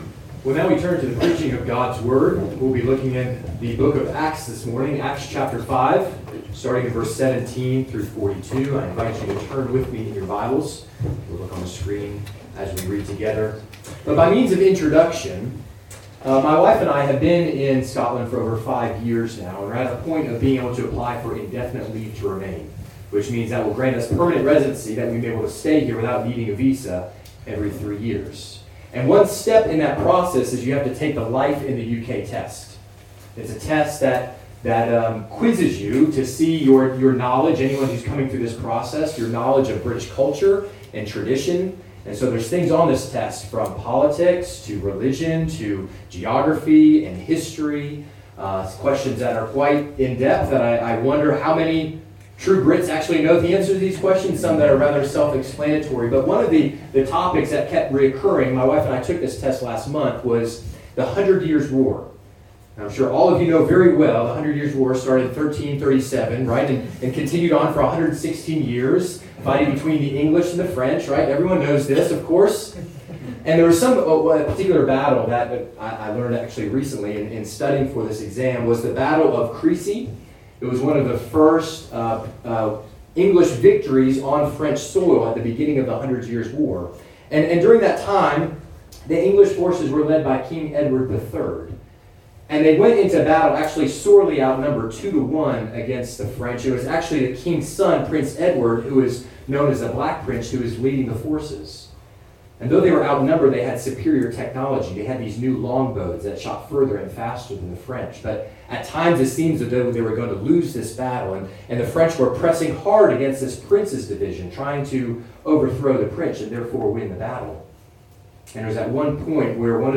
A link to the video recording of the 11:00am service, and an audio recording of the sermon.